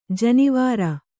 ♪ janivāra